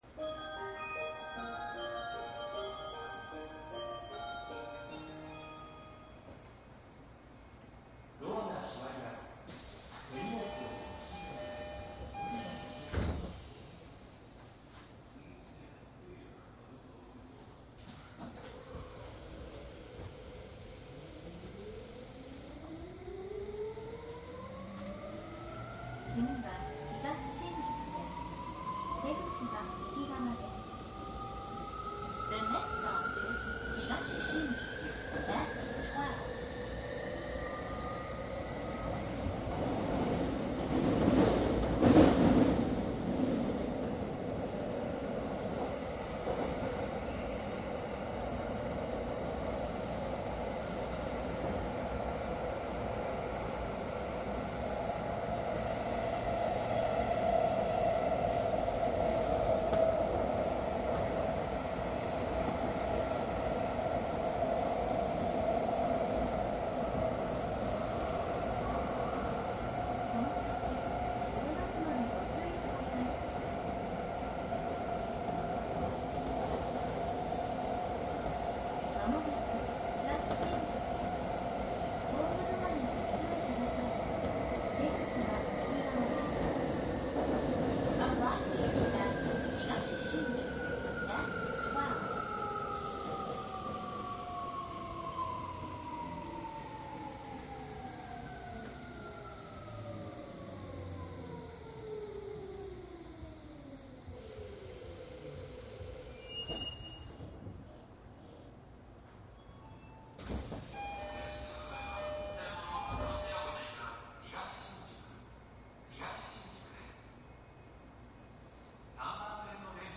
東京メトロ１７０００系8両編成 副都心線     和光市・所沢方面 走行音  CD♪
東横線内の録音と副都心線、西武線内を収録。東横線は都内の部分を収録。
■【各停】渋谷→和光市
マスター音源はデジタル44.1kHz16ビット（マイクＥＣＭ959）で、これを編集ソフトでＣＤに焼いたものです。